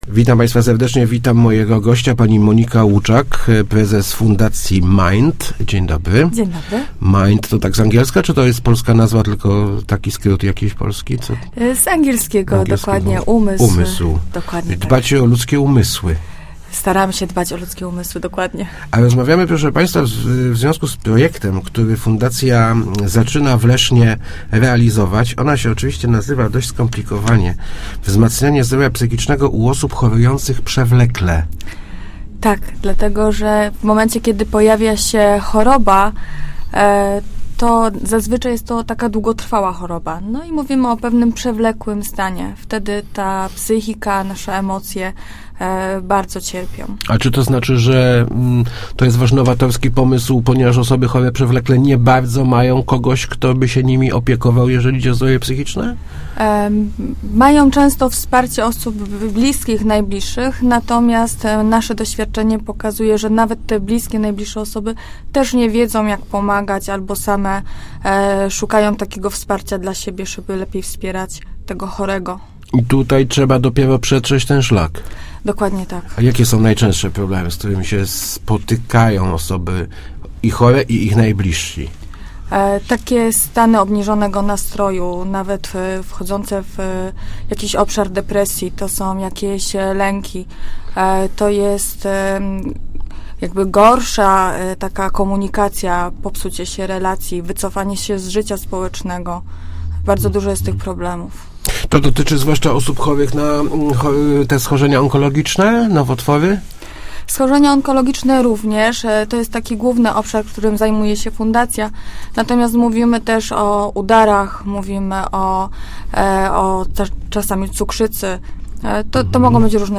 Start arrow Rozmowy Elki arrow Wsparcie w nieszczęściu